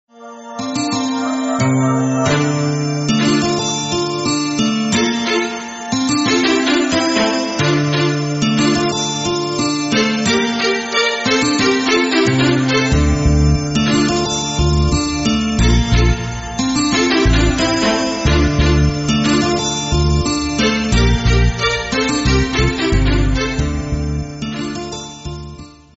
Estilo: Sinfónico
Pista instrumental para Cine, Triste, Dulce, Fantasia, Amor